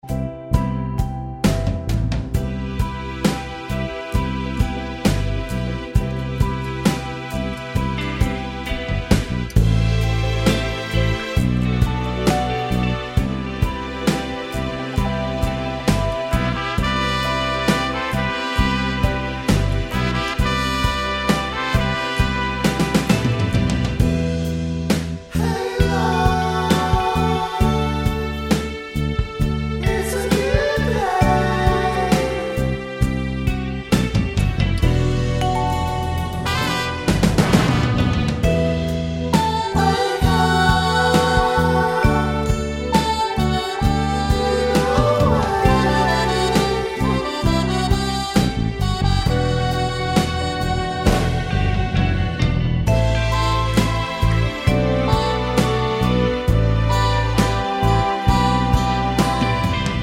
no Backing Vocals Soul / Motown 3:18 Buy £1.50